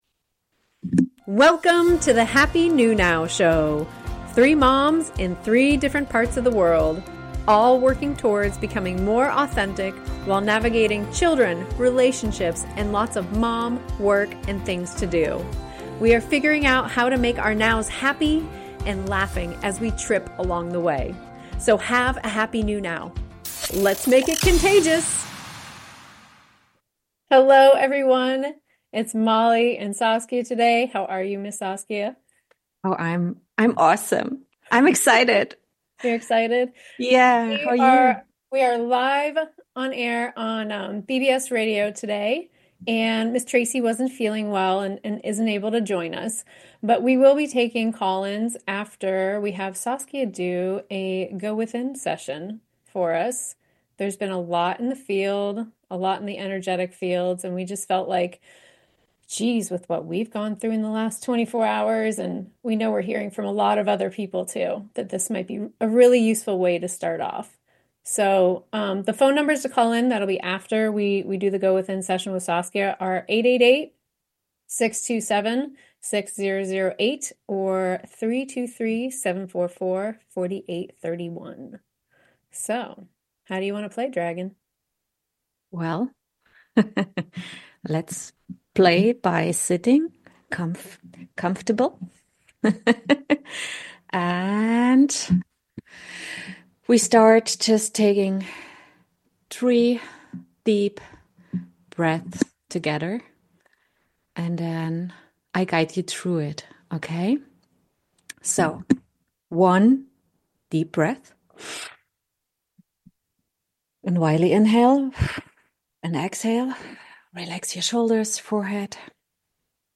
Talk Show Episode, Audio Podcast, Group Go Within Session, Callers and Discussing These Times! Ep 7 and Join us to go within as a group, take calls, and discuss what we are feeling in the collective field.